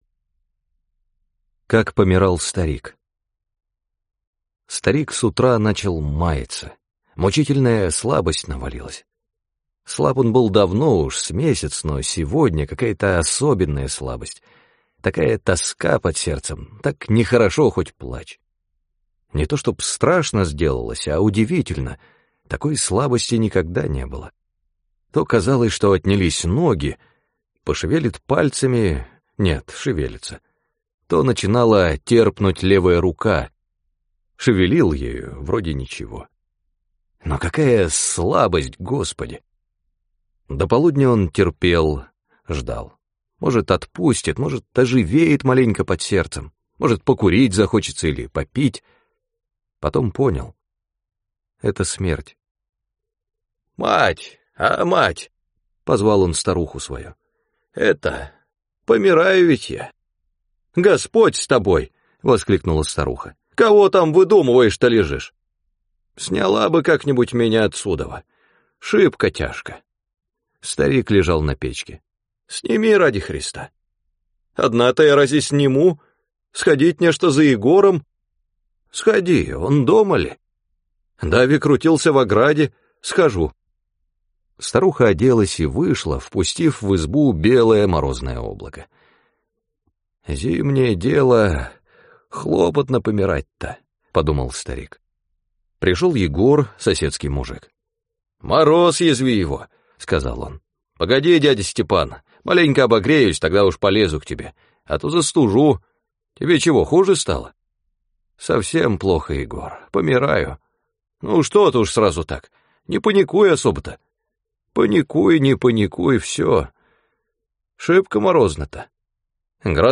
На данной странице вы можете слушать онлайн бесплатно и скачать аудиокнигу "Как помирал старик" писателя Василий Шукшин.